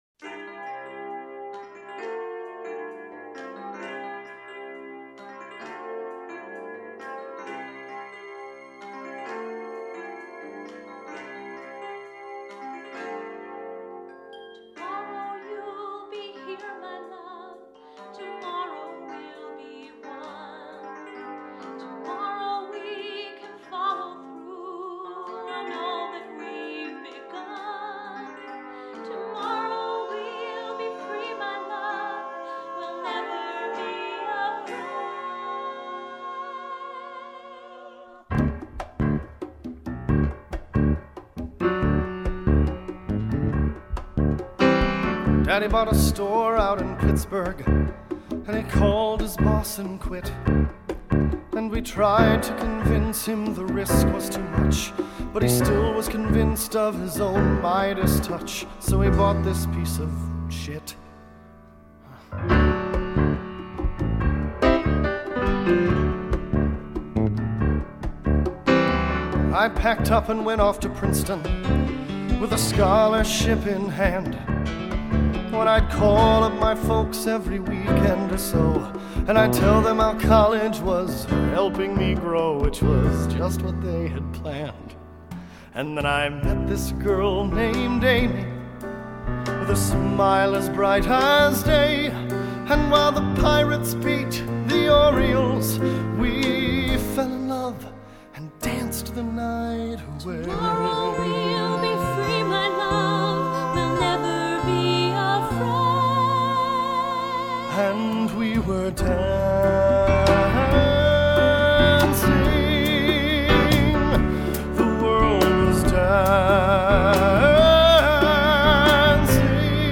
(Excuse the 1990s orchestrations.)